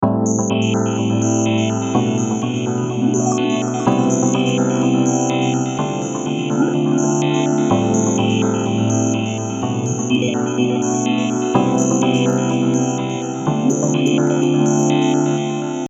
さて、ディレイをかけてみましょうか。
味気ないというか素朴なピアノのボイシングにリズムや動きが足され、ディレイによって広がりが出てきた感じですね。